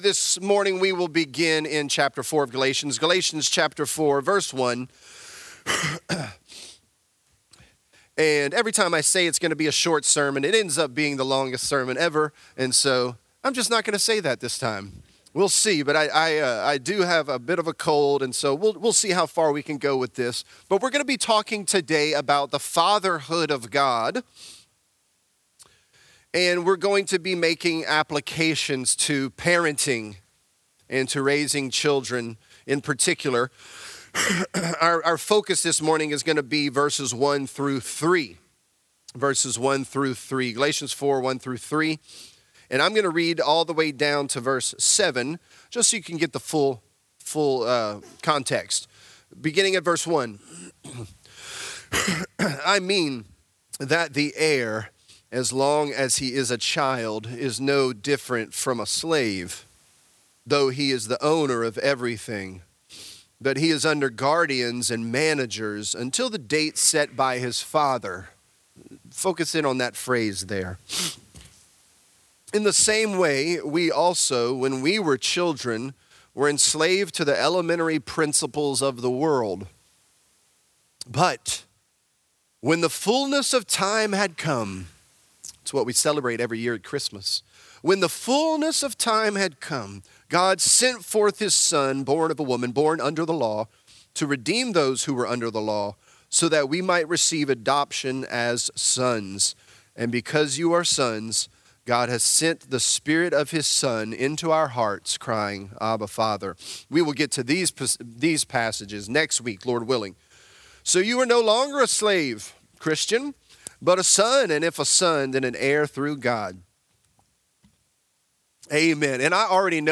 Galatians: Elementary Principles & Parenting | Lafayette - Sermon (Galatians 4)